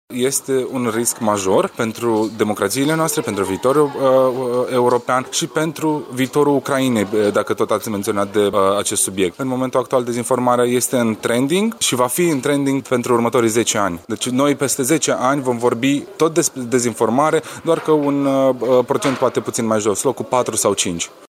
Peste 300 de personae, marea majoritate fiind formată din studenți, au participat la dezbaterea cu tema “Dezinformarea şi inteligenţa artificială – rolul UE în combaterea acestui fenomen” de la Universitatea “Aurel Vlaicu” din Arad.